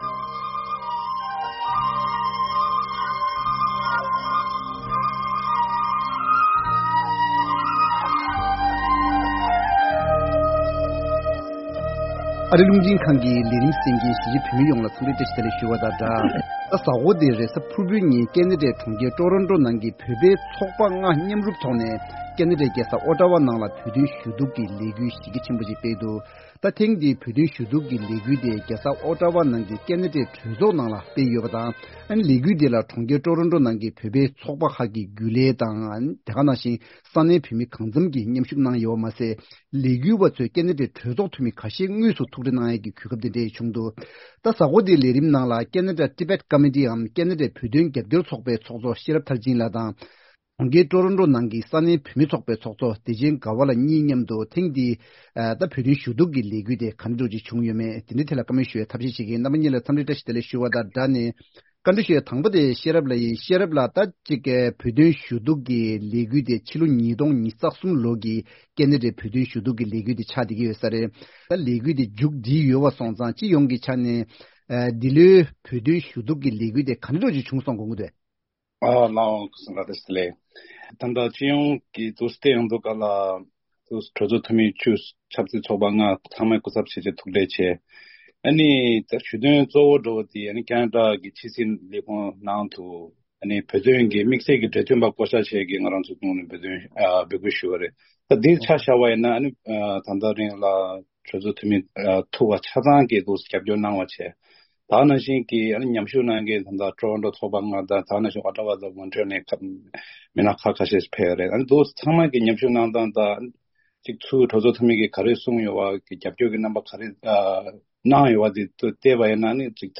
གླེང་མོལ་ཞུས་པ་ཞིག་གསན་གྱི་རེད།